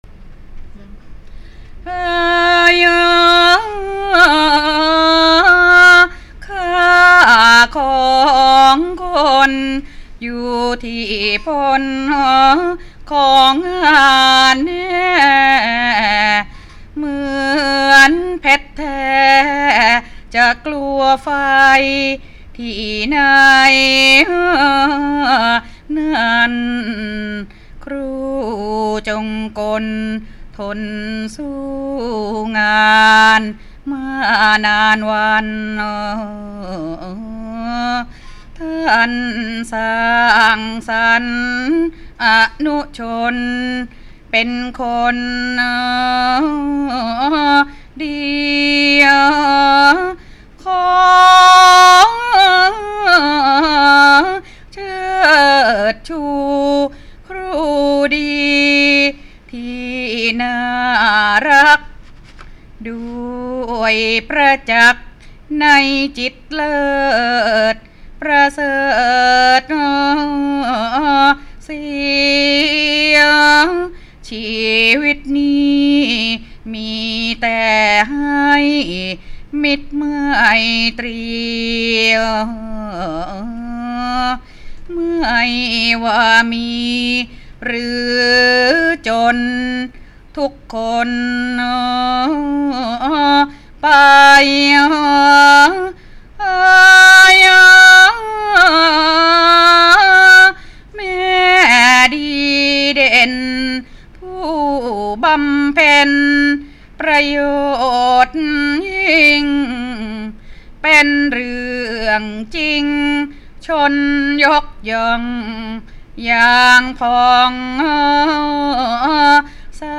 ทำนองเสนาะ ร้อยกรอง